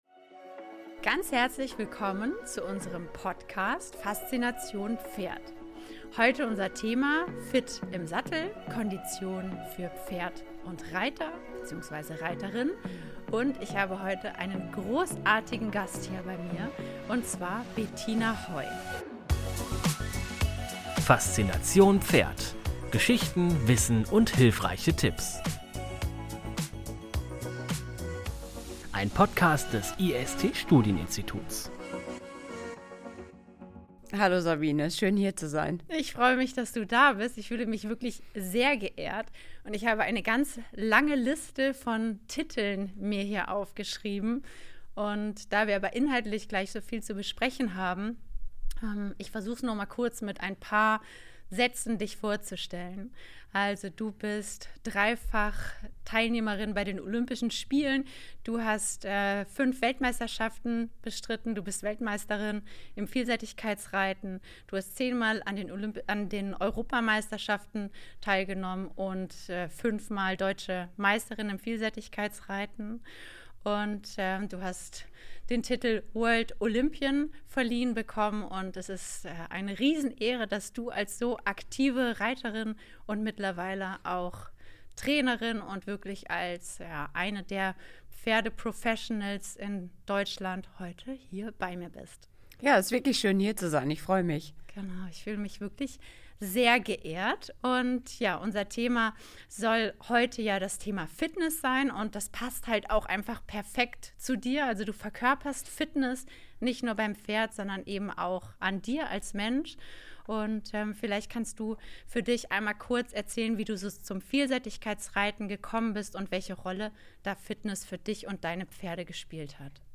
Zu Gast: Olympionikin Bettina Hoy Wenn Reiten leicht aussieht, steckt meist harte Arbeit dahinter – nicht nur beim Pferd.